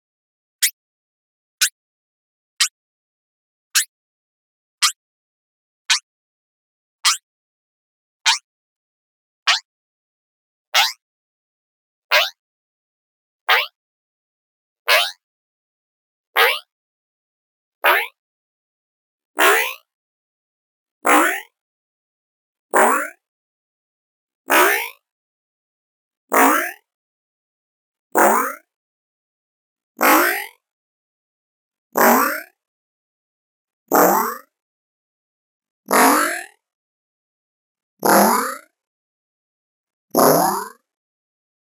decimated boing
boing boink bonk cartoon flex flexed flexing plastic sound effect free sound royalty free Movies & TV